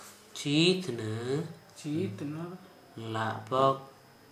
Dialect: Hill